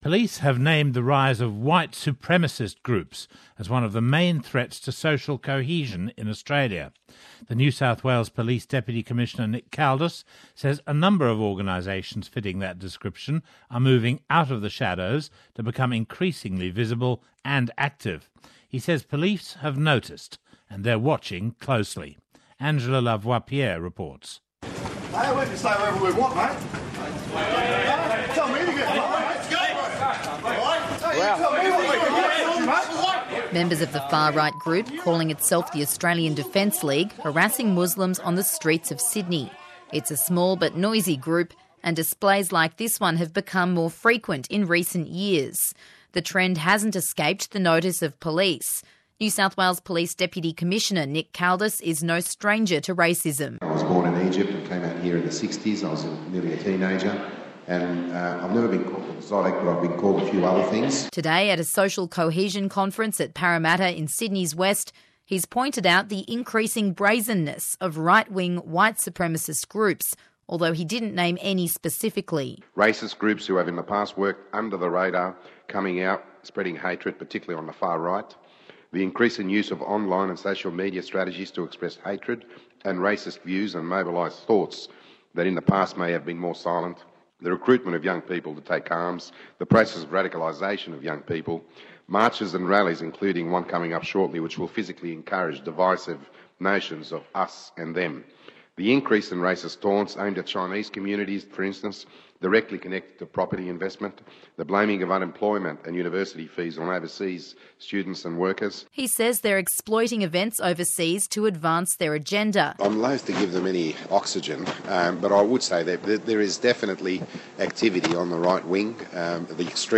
He was speaking at a community cohesion conference at the University of Western Sydney, in Parramatta.